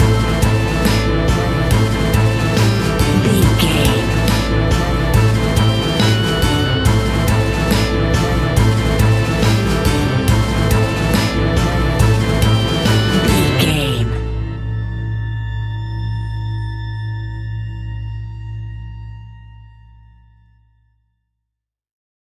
Aeolian/Minor
ominous
haunting
eerie
electric organ
strings
acoustic guitar
harp
synthesiser
drums
percussion
horror music